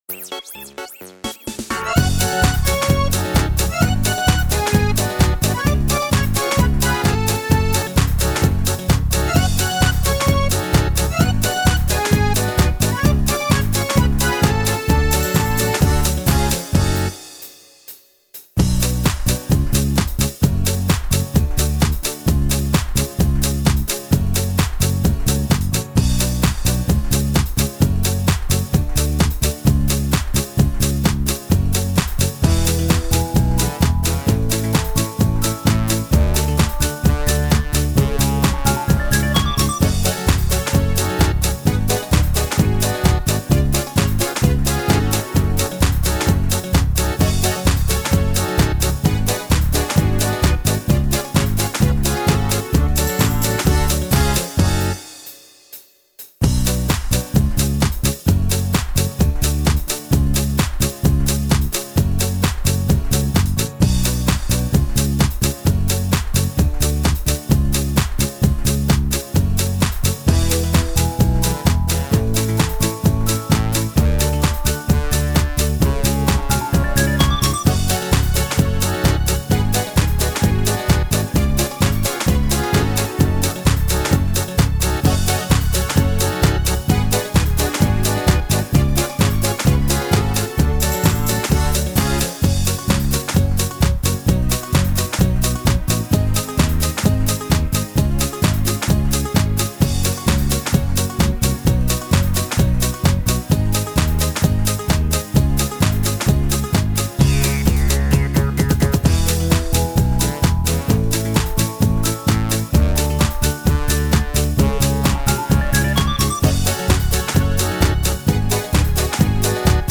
минусовка версия 39916